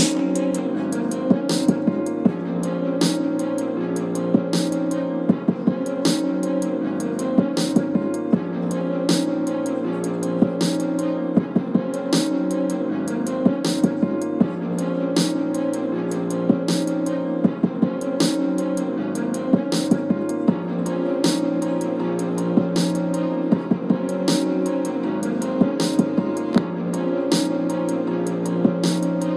Knistern und Knackser in FL Studio bei Projektwiedergabe
Ist halt jetzt nur eine iPhone Aufnahme. Bei 0:26 ist das knacksen eindeutig zu hören.